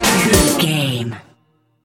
Uplifting
Aeolian/Minor
Fast
drum machine
synthesiser
electric piano
Eurodance